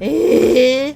Worms speechbanks
Jump1.wav